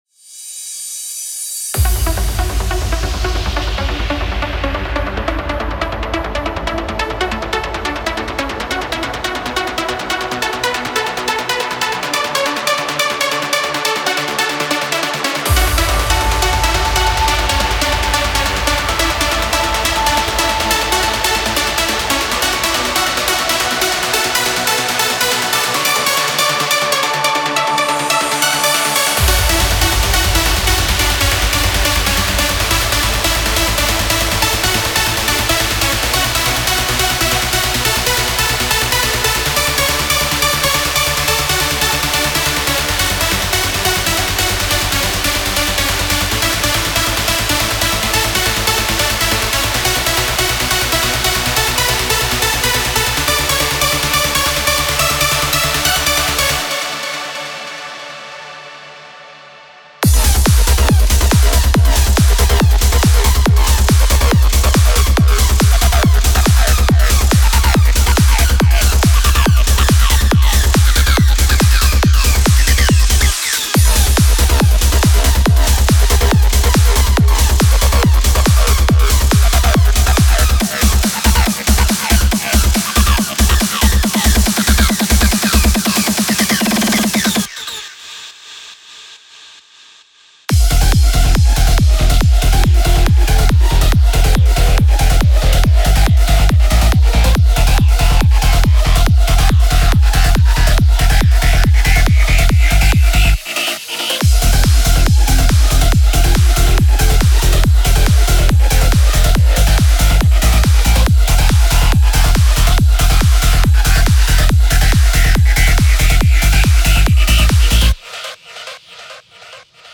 Genre: Trance Uplifting Trance